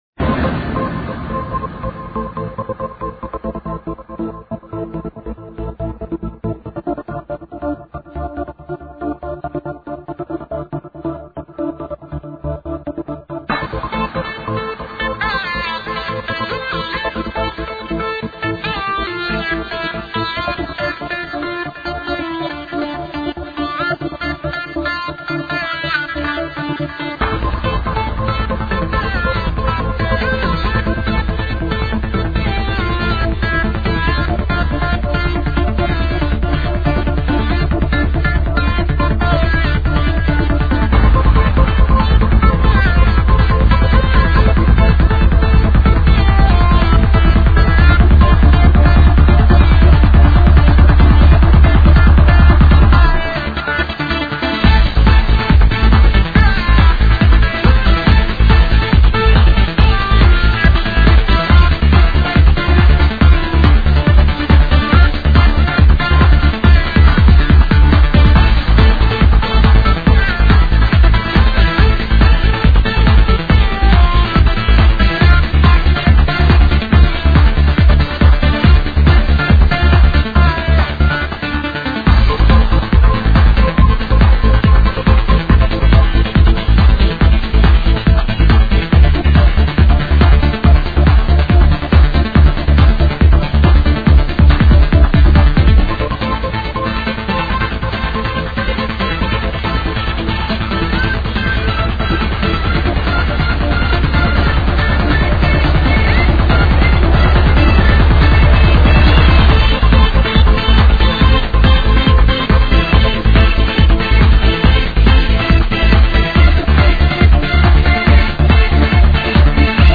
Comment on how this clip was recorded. here is much better quality